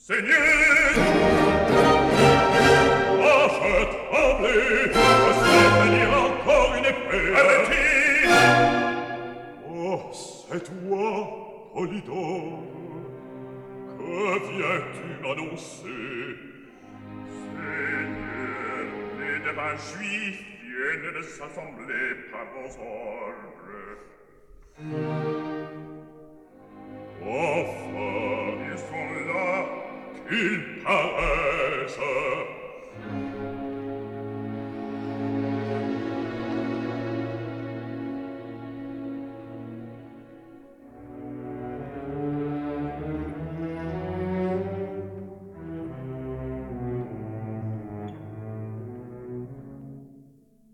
A short, wild dance in 7/4 time.
Stereo recording made in London